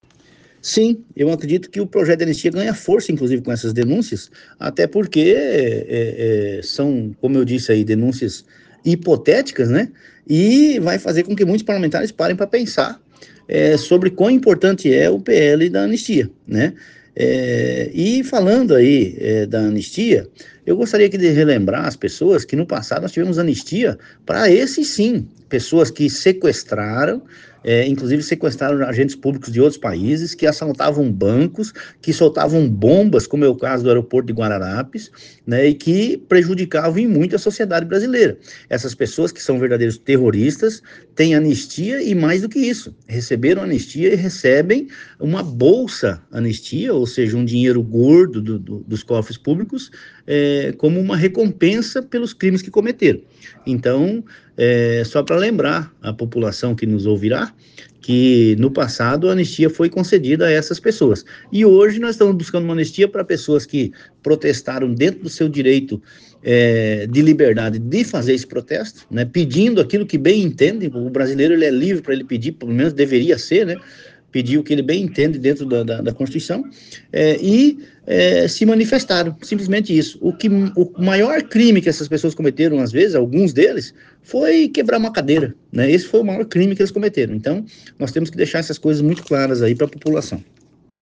OUÇA A ENTREVISTA DO DEPUTADO GILBERTO CATTANI